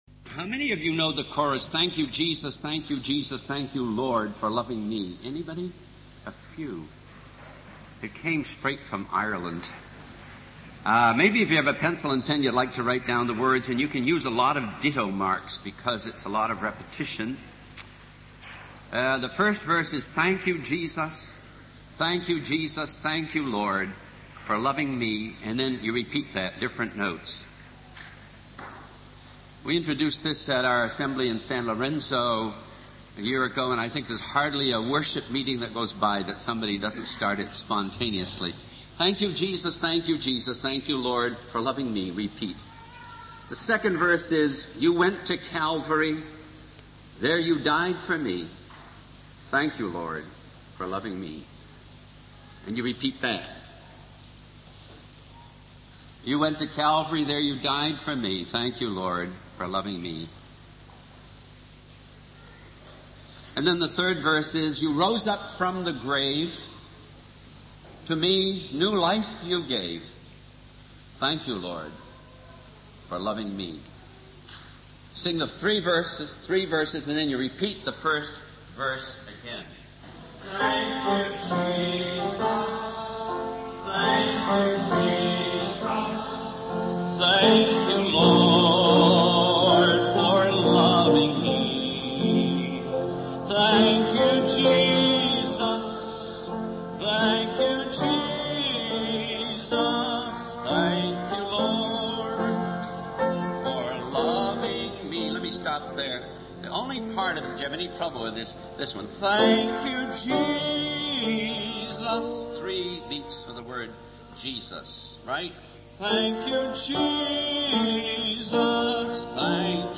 In this sermon, the preacher discusses the importance of endurance in the Christian life. He shares a story about the coronation ceremony of the Queen of England, highlighting the Archbishop of Canterbury's proclamation of the Queen's authority.